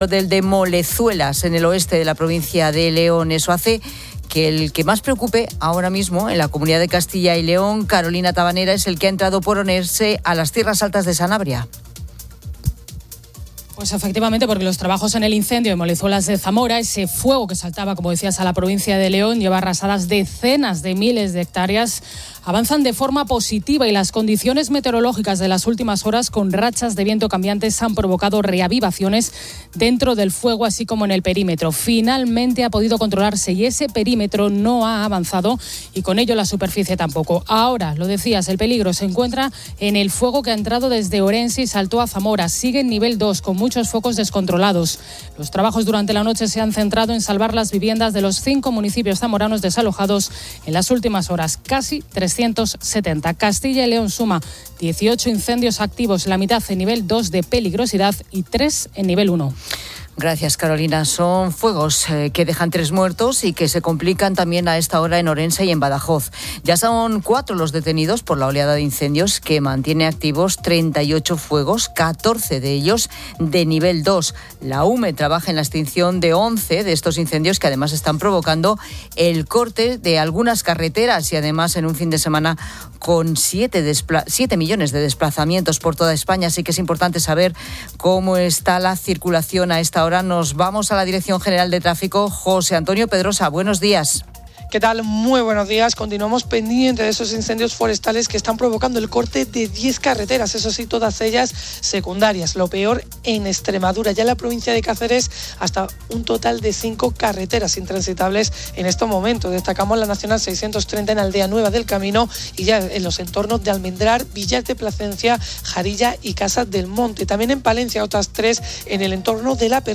Una entrevista con la Brigada de Patrimonio Histórico de la Policía Nacional abordó el robo de arte y los expolios arqueológicos, un mercado ilícito millonario con baja tasa de recuperación, destacando el desafío de las falsificaciones.